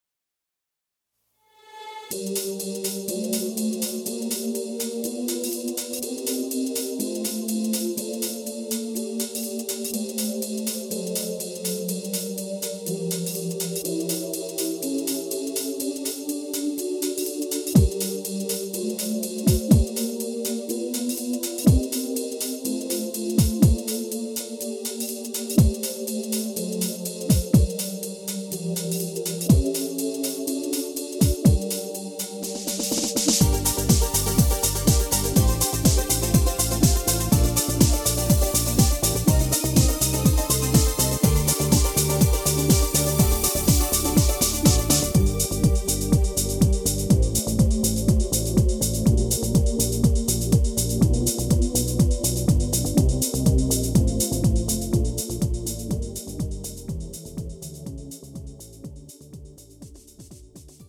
음정 (-1키)
장르 가요 구분 Premium MR